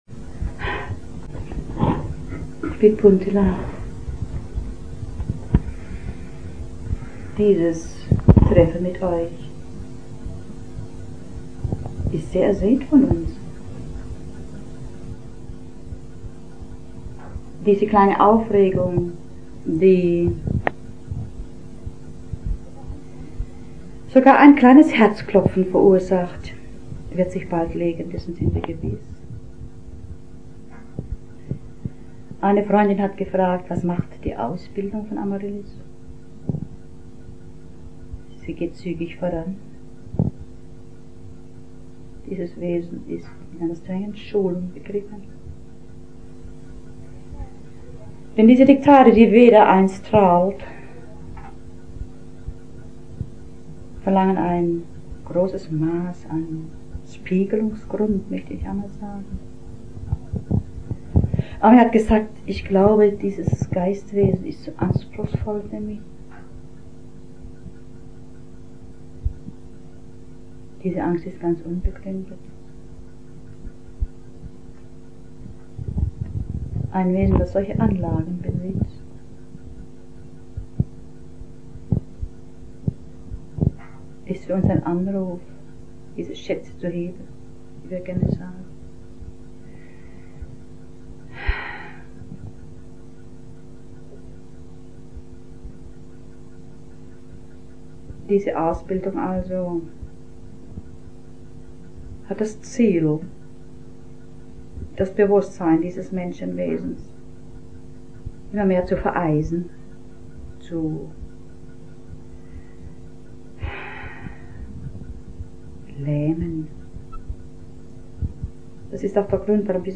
50. Vortrag
50. Bandaufnahme in Ravensburg 26.1.1983